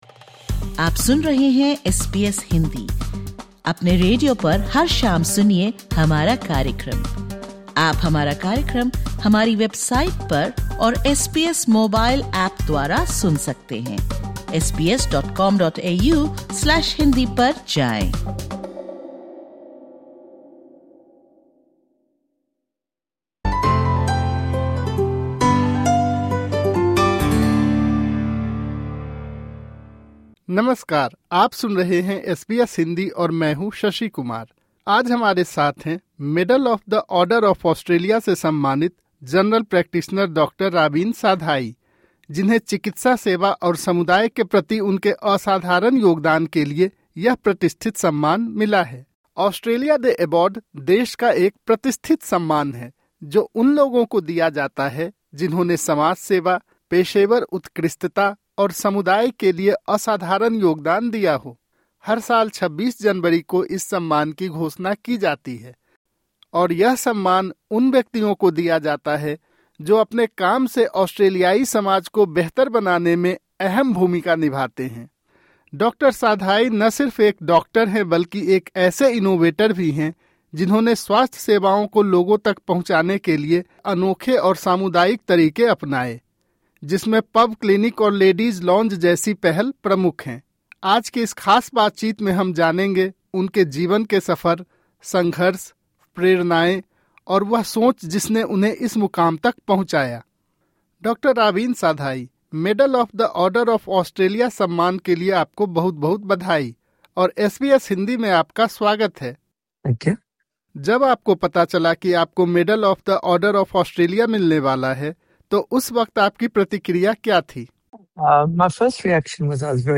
एसबीएस हिन्दी के साथ इस विशेष बातचीत में